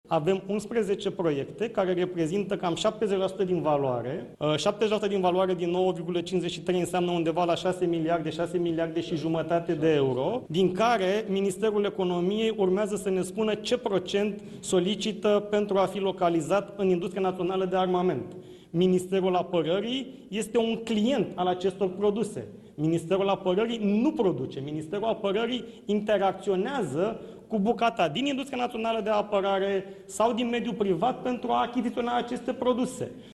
Ministrul Apărării, Radu Miruță: „Avem 11 proiecte care reprezintă aproximativ 70% din valoare”